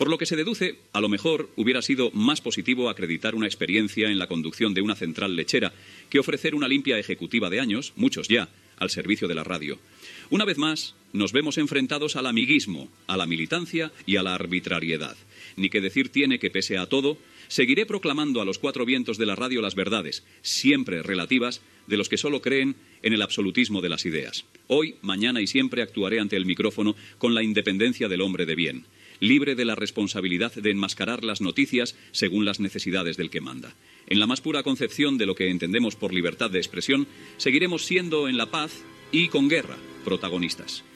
Comentari sobre el vicepresident del govern espanyol Alfonso Guerra.
Info-entreteniment